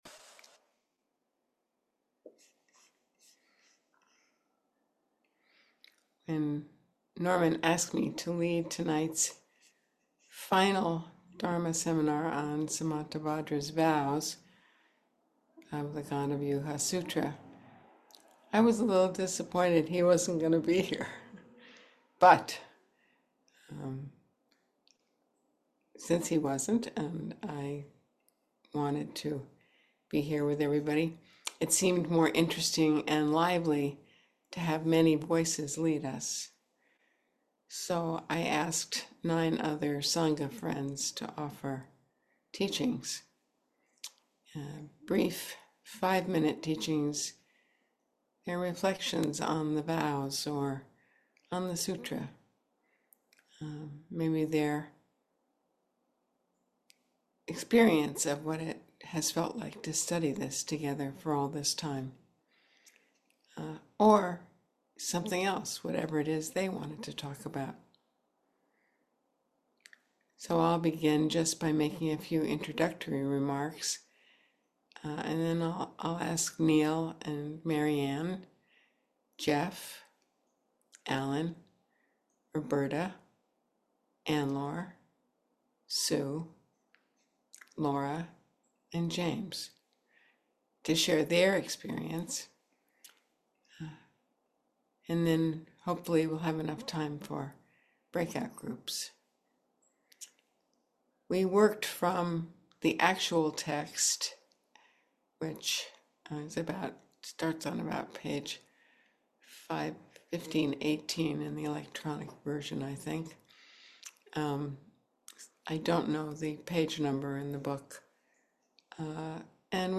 Gandavyuha Sutra (Avatamsaka Sutra Chapter 39) – Talk 16